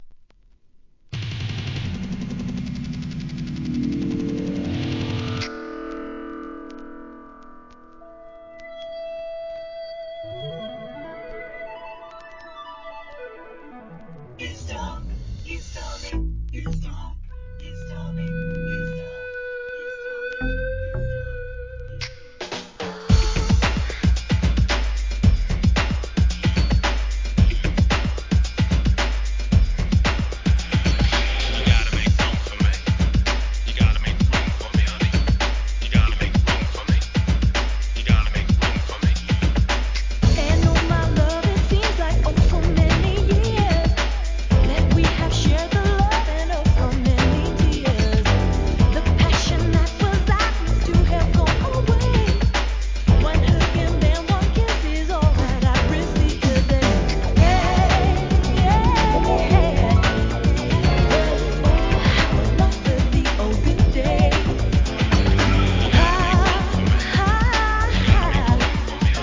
HIP HOP/R&B
疾走感溢れる爽快なR&B!